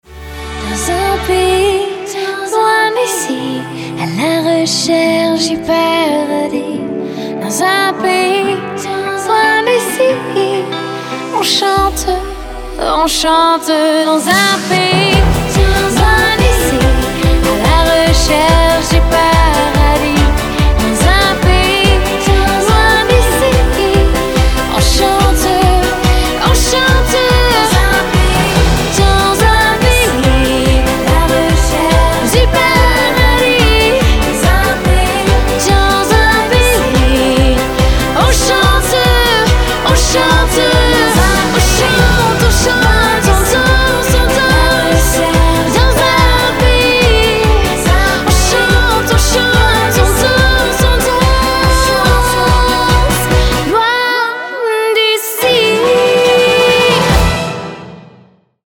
• Качество: 320, Stereo
французские